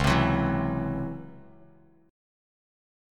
C#mM11 Chord
Listen to C#mM11 strummed